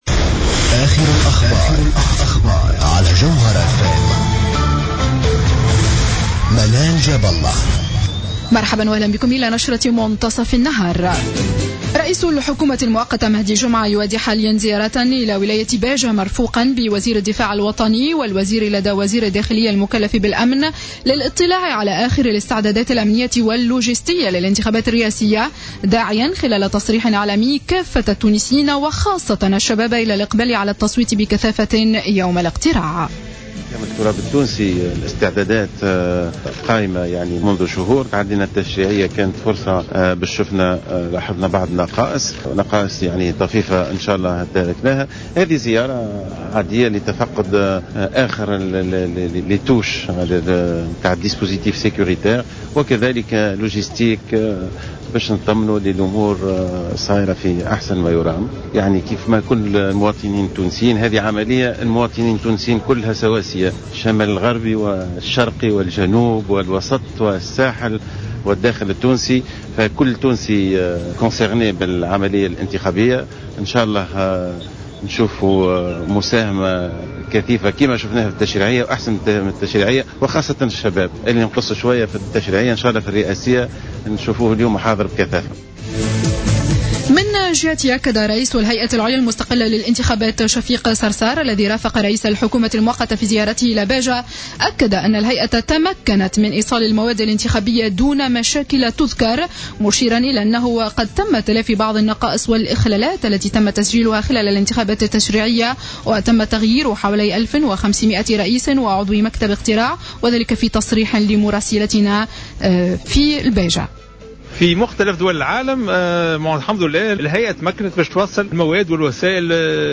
نشرة أخبار منتصف النهار ليوم السبت 22-11-14